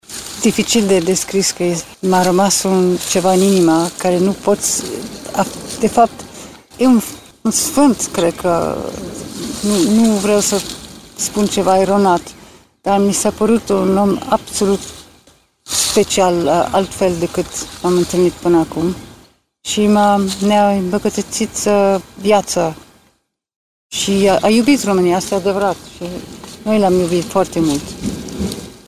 Majestatea Sa Margareta, Custodele Coroanei, l-a întâlnit pe Papa Francis de mai multe ori, și a povestit ce impresie i-a lăsat.